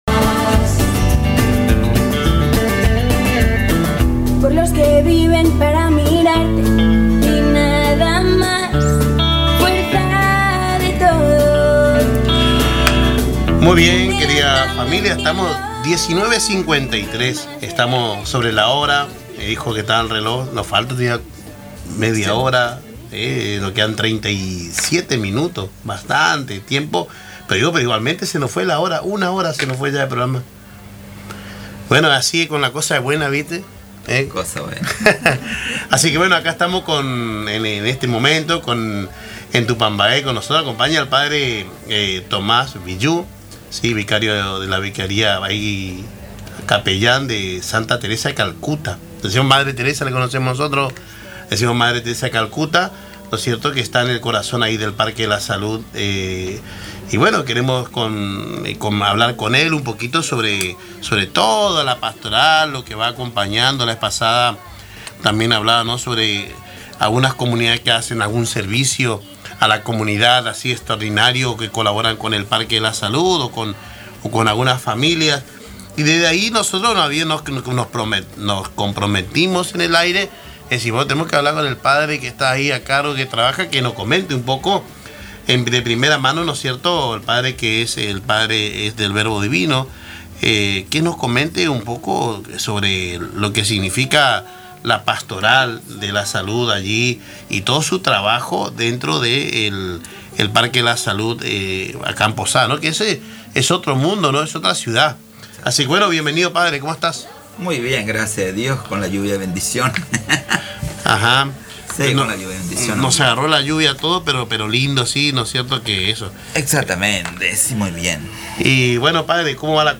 En el programa Caminando Juntos por Radio Tupambaé se entrevistó